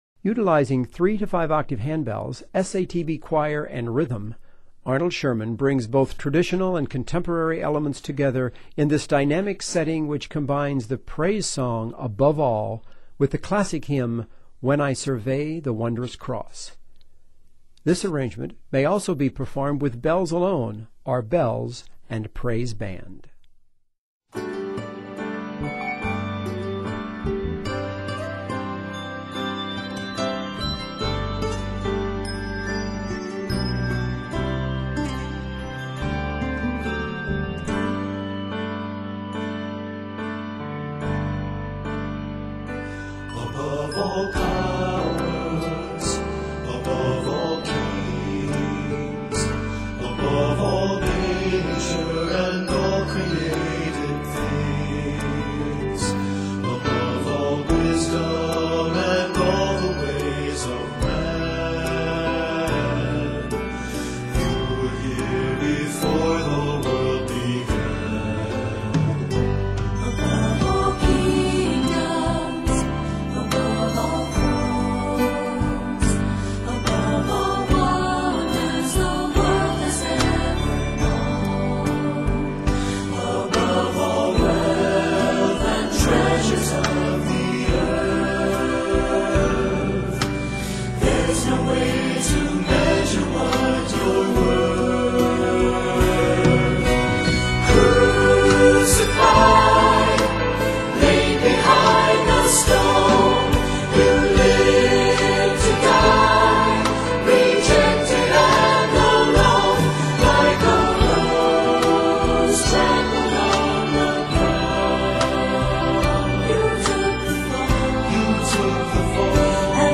Utilizing handbells, SATB choir, and various instruments